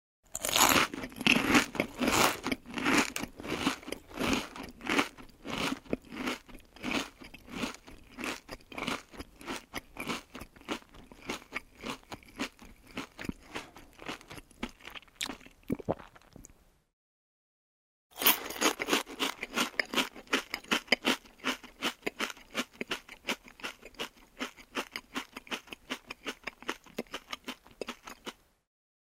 Звуки поедания еды
Хрустящий звук поедания картофельных чипсов